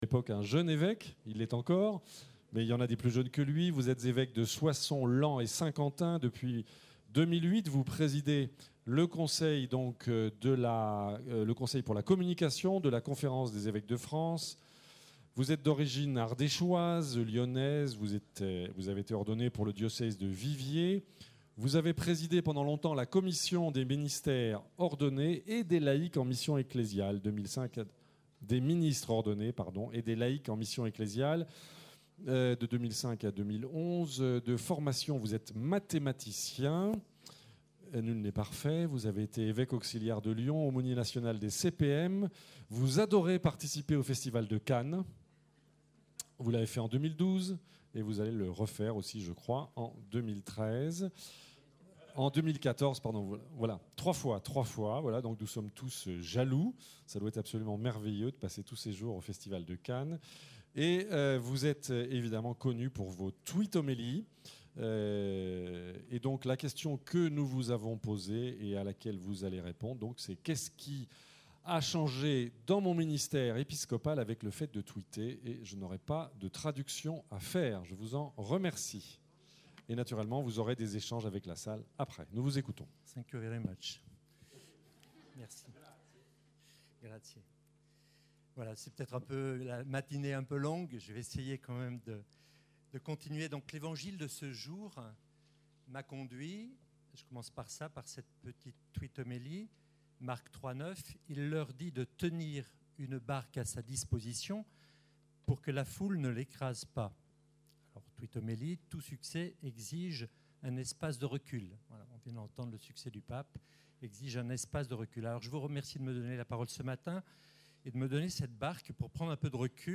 Conférence prononcée par Mgr Hervé Giraud, évêque de Soissons, président du Conseil pour la communication de la Conférence des évêques de France.